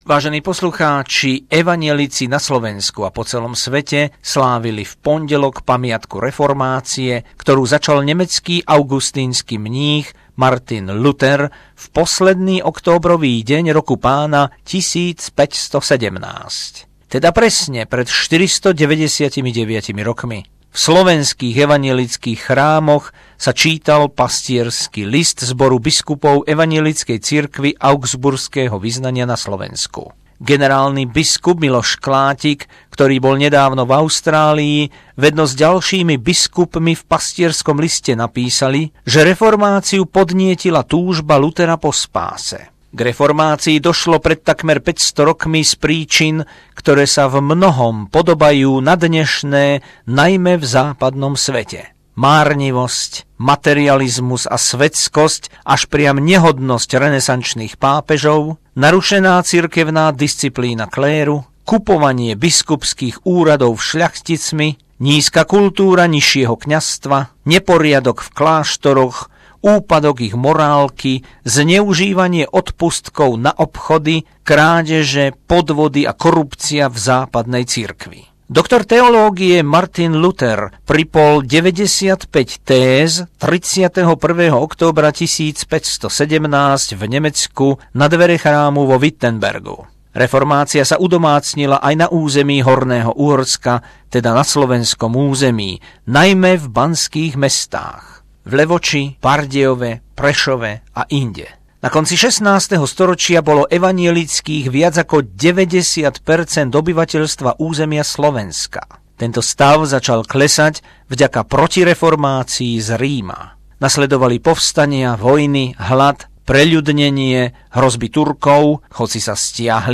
Spomienka na reformáciu Martina Luthera roku 1517 a ukážky z recitácie Dušana Jamricha, čerstvého sedemdesiatnika